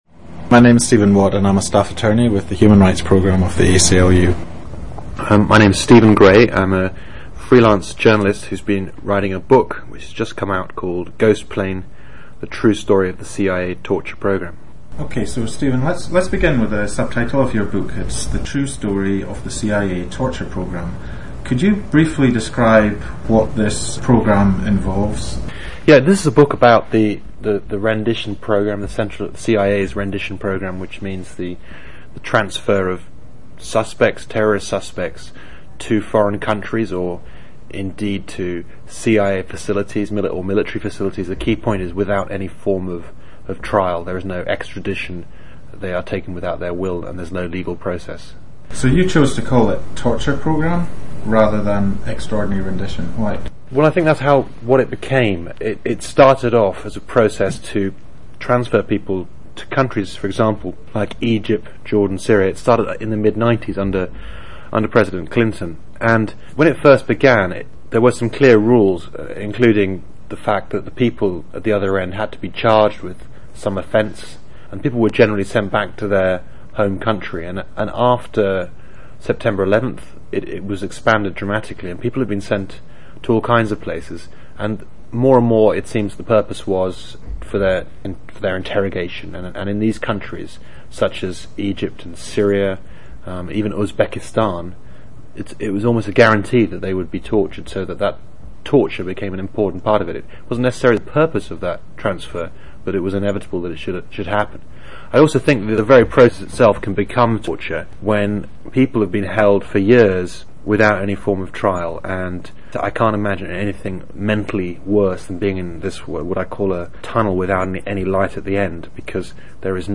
A Conversation About the CIA Torture Program